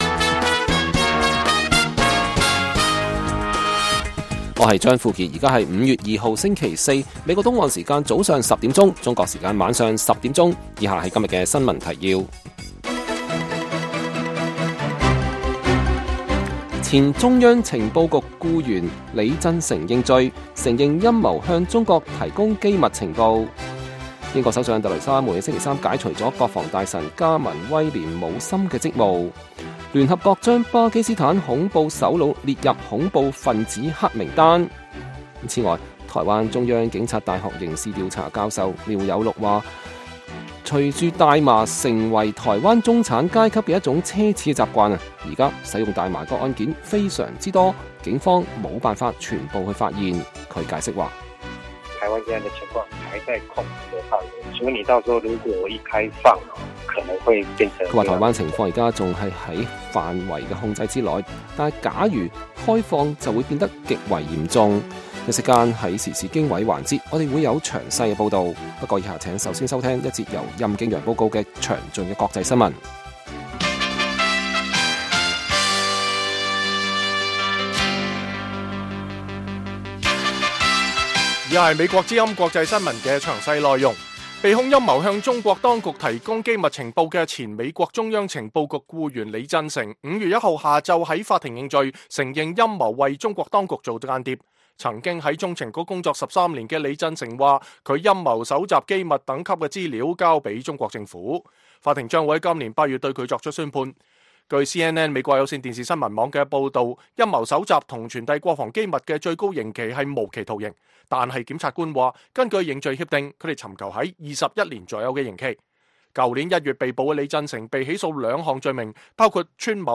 粵語新聞 晚上10-11點
北京時間每晚10－11點 (1400-1500 UTC)粵語廣播節目。內容包括國際新聞、時事經緯、英語教學和社論。